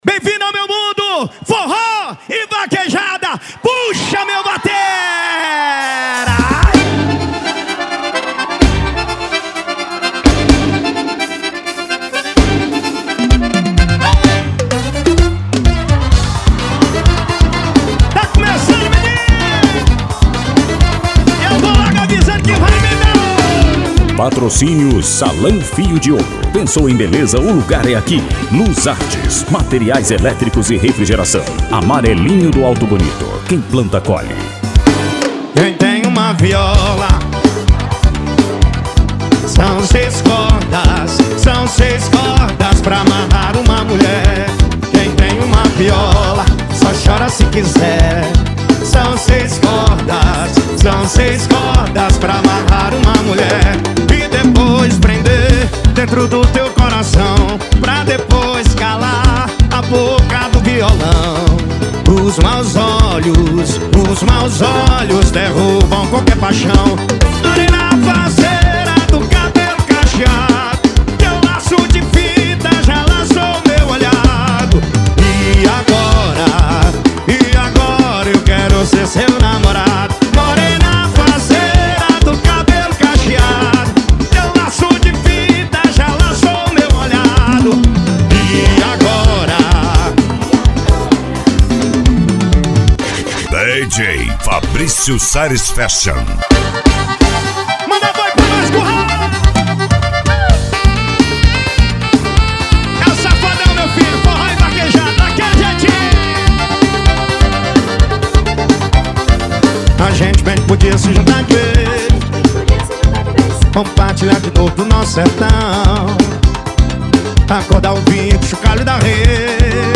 Arrocha
Funk
SERTANEJO
Sertanejo Universitario
Sets Mixados